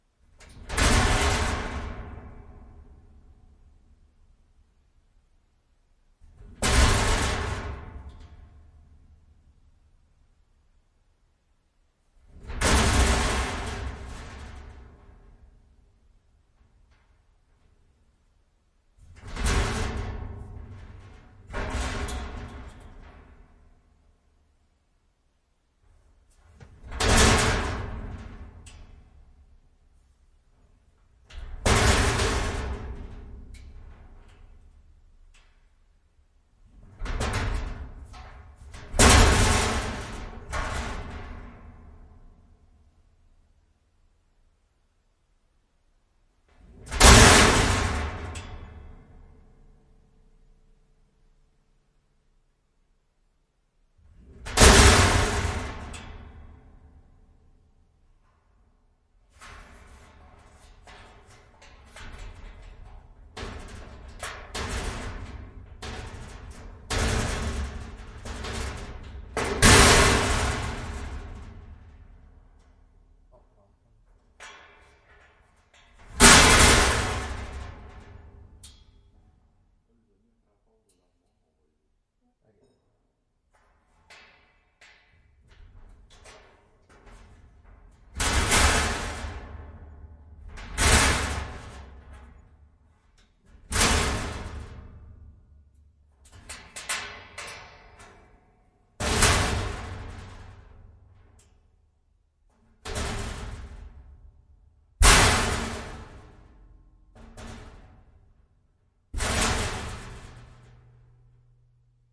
金属店" 金属储物柜内部发出砰砰的响声
描述：金属储物柜thuds拨浪鼓inside.flac
Tag: 内部 金属 重击声 储物柜 拨浪鼓